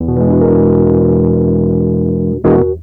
05 Rhodes 5.wav